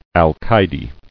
[al·cay·de]